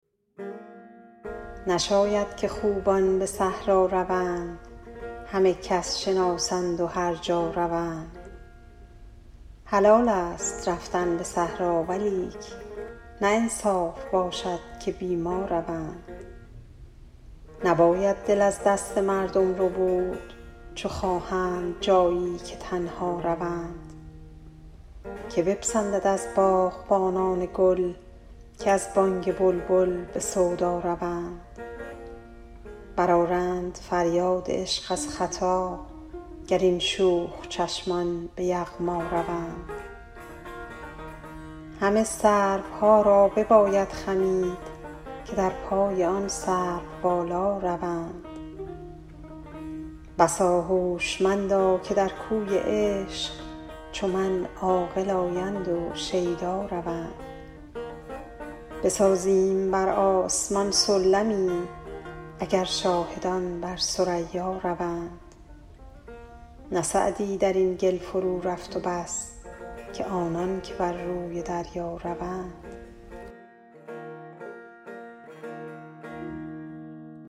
خوانش شعر